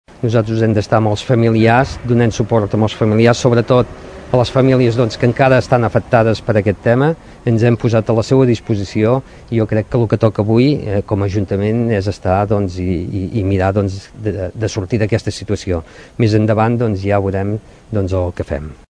Unes 300 persones van guardar ahir un minut de silenci a Caldes de Malavella  en record a la nena de 6 anys que va perdre la vida per l’accident del castell inflable i per transmetre l’escalf de la població als sis infants ferits de diversa consideració. Salvador Balliu és l’alcalde de Caldes.